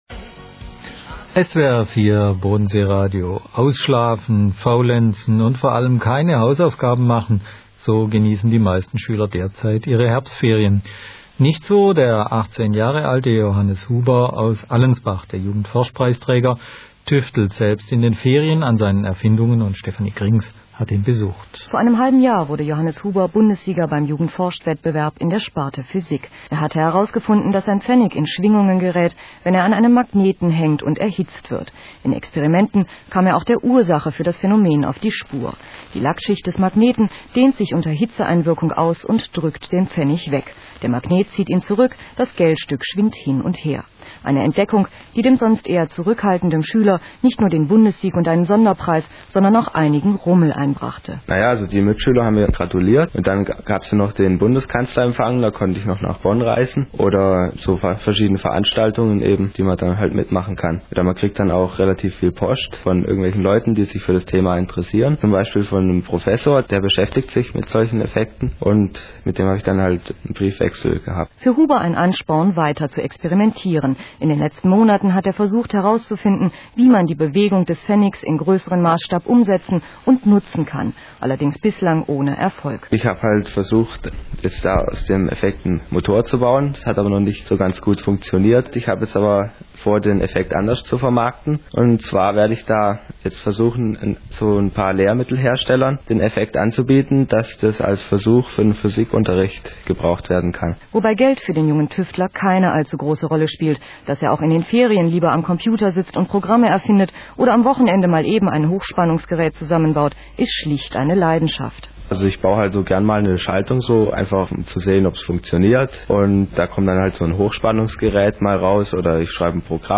SWR4-Radiointerview